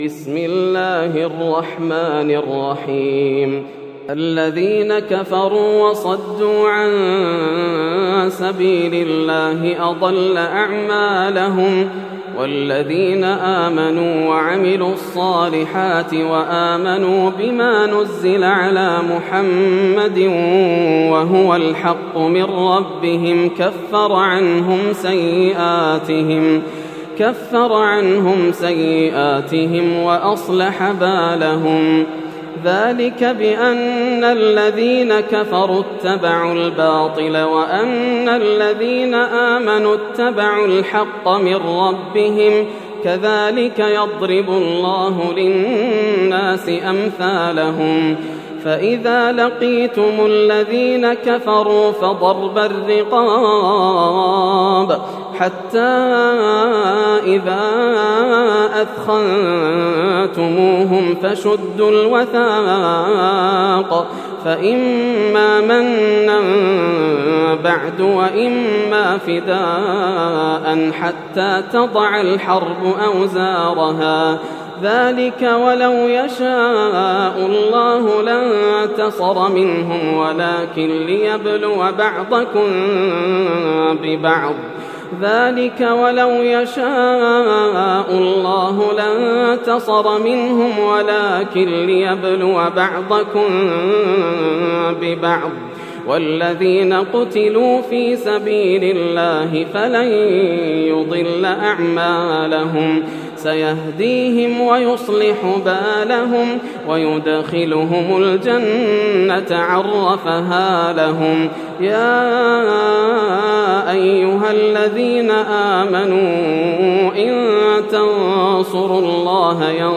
سورة محمد > السور المكتملة > رمضان 1431هـ > التراويح - تلاوات ياسر الدوسري